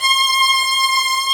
Index of /90_sSampleCDs/Roland LCDP13 String Sections/STR_Combos 2/CMB_Hi Strings 2
STR VIOLIN03.wav